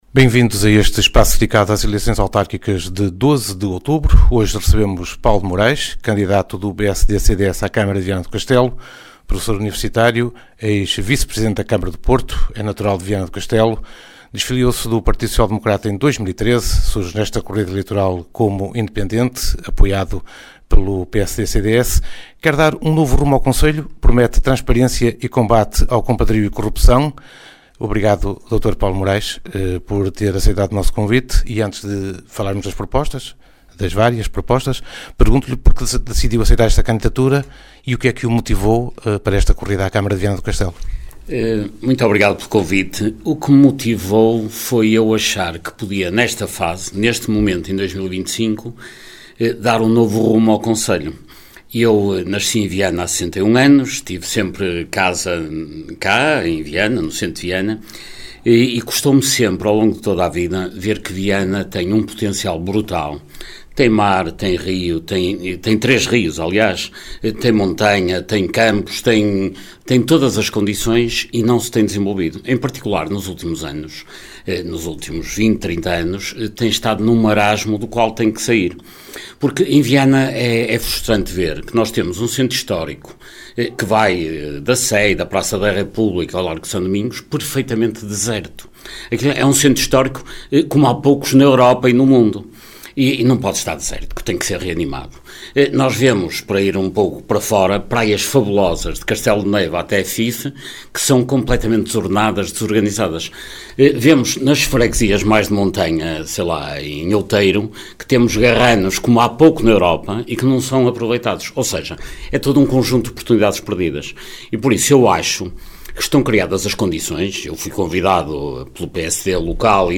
Ação conjunta da Rádio Geice FM e da Rádio Alto Minho, que visa promover um ciclo de entrevistas aos candidatos à presidência da Câmara Municipal de Viana do Castelo.